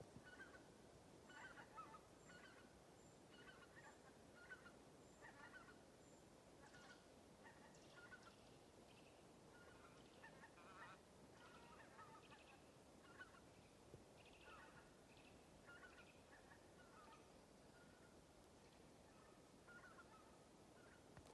Zoss Anser sp., Anser sp.
Administratīvā teritorijaStrenču novads
StatussDzirdēta balss, saucieni